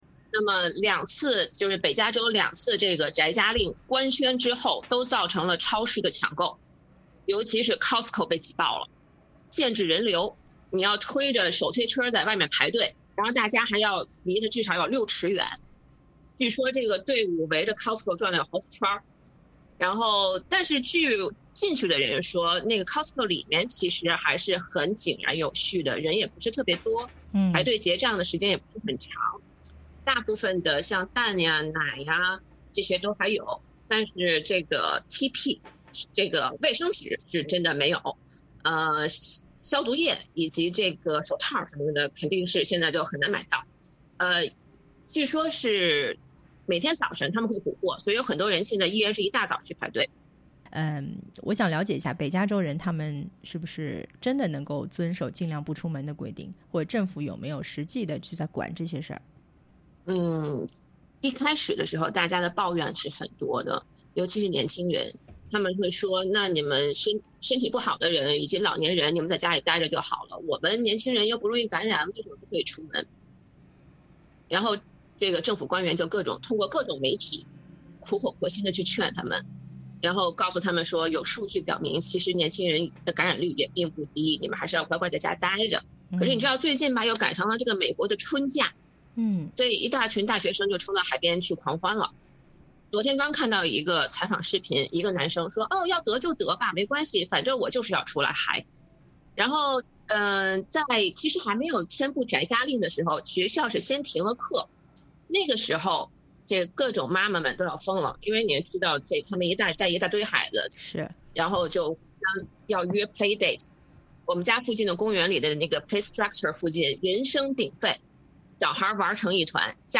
她在采访中也特别用“宅家令”来指代这一措施。